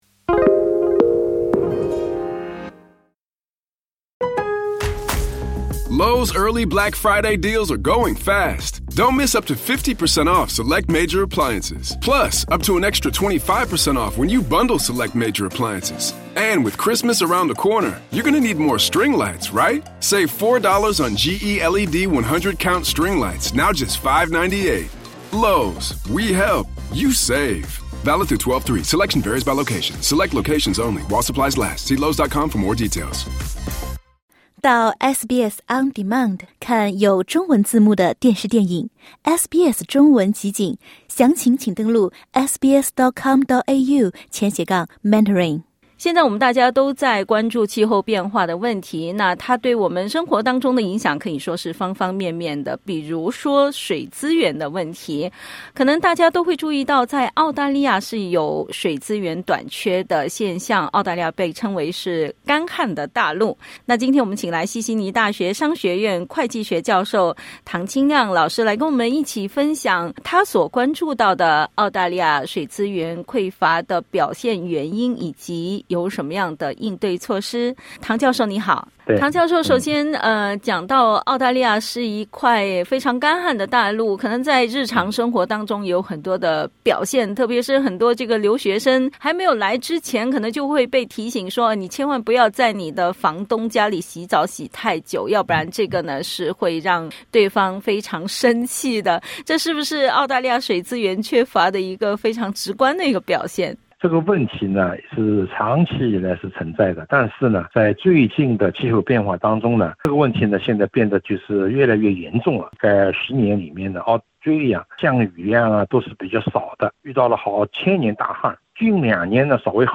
（点击音频收听详细采访） 澳洲大陆是世界上有人类居住的大陆中最干旱的地区之一,水资源十分稀缺，其脆弱的生态系统正遭受气候变化、自然灾害、土地开发以及其他密集型人类活动的严重威胁。